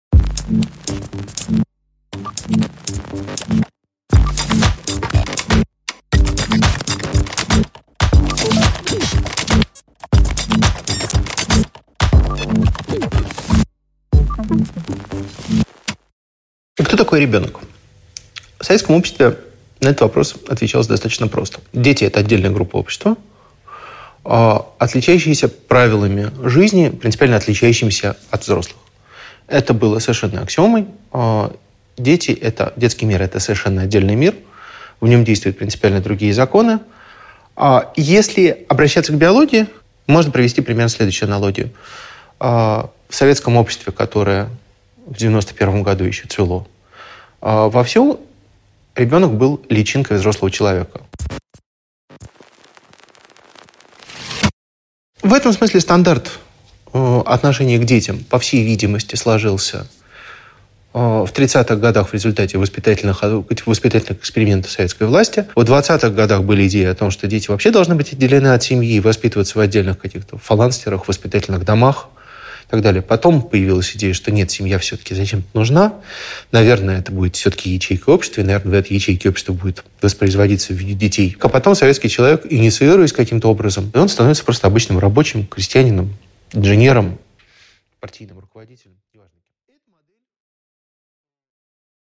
Аудиокнига Детский мир: страна для новых людей | Библиотека аудиокниг